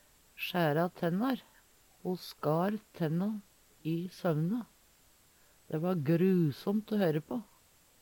sjæra tennar - Numedalsmål (en-US)